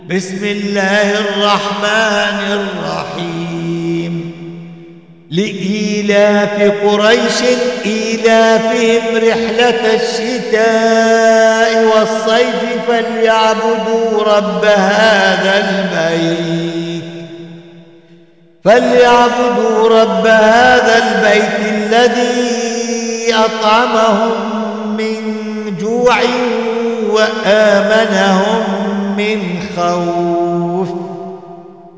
دروس التجويد وتلاوات